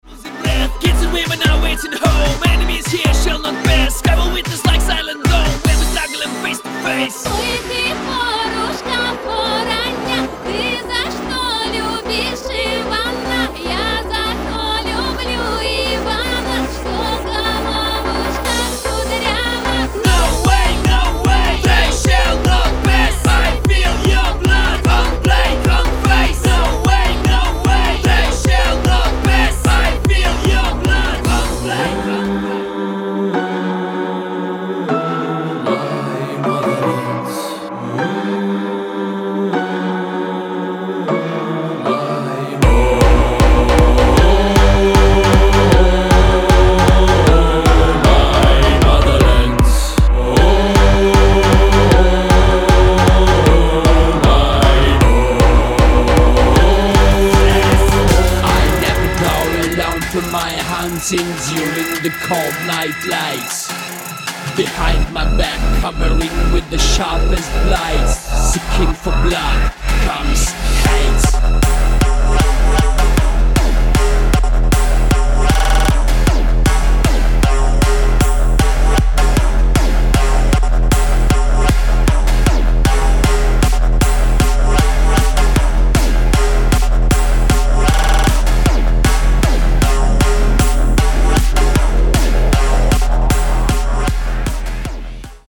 Those are more like songs, neither tracks.
So that is how i see dark hardbass.